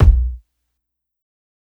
Kicks
KICK_DETER.wav